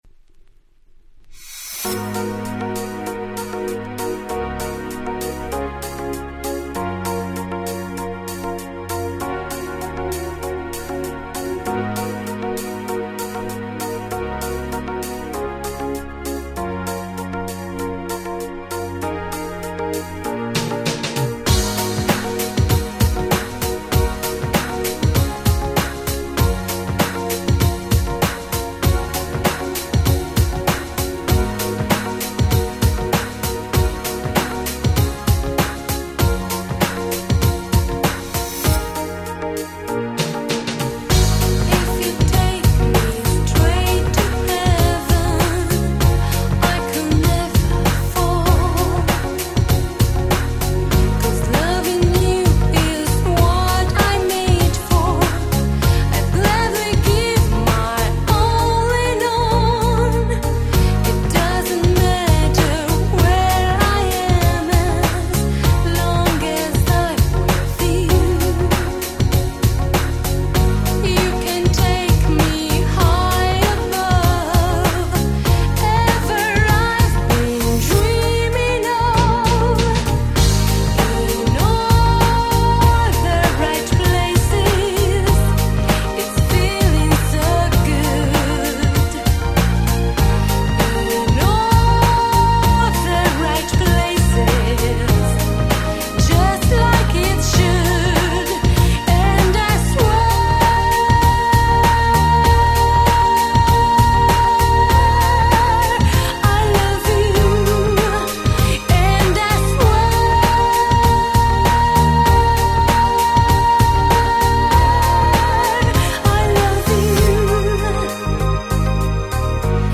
【Media】Vinyl 12'' Single
93' Super Nice Cover Ground Beat !!